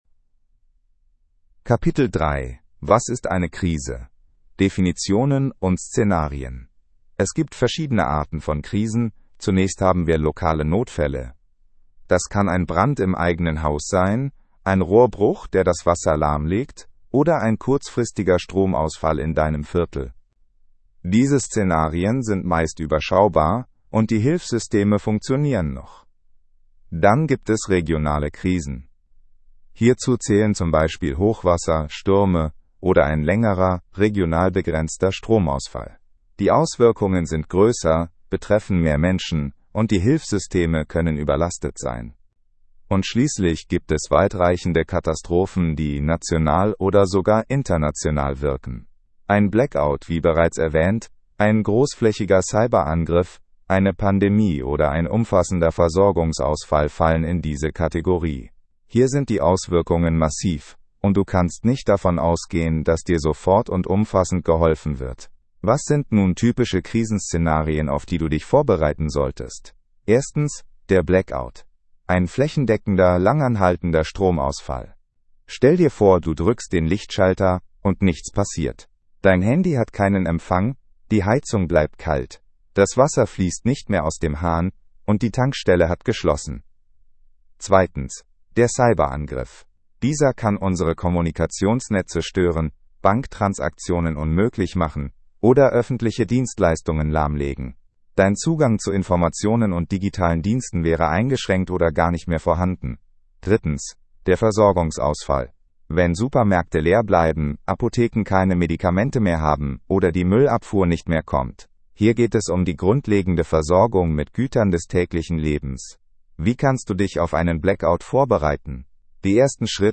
Hörprobe Katastrophenschutz
ki-audio-horprobe-katastrophenschutz.mp3